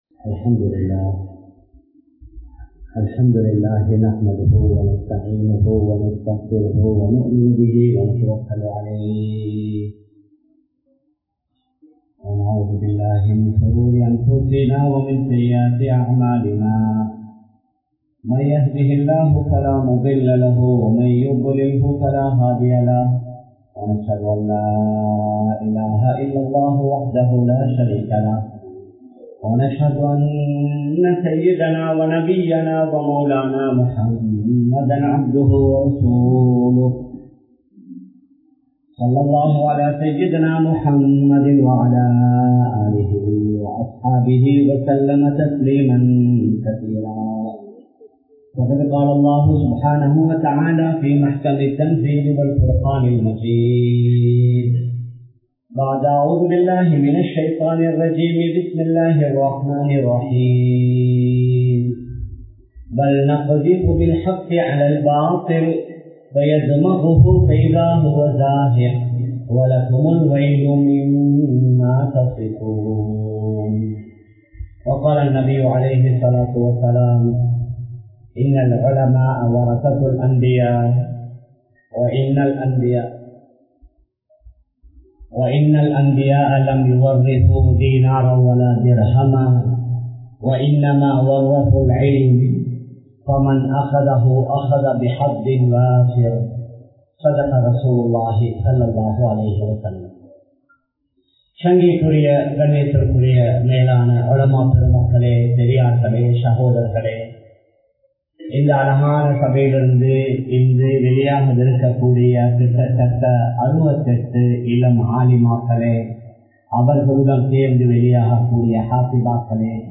Thaaimaarhalin Thiyaahangalin Vilaivu (தாய்மார்களின் தியாகங்களின் விளைவு) | Audio Bayans | All Ceylon Muslim Youth Community | Addalaichenai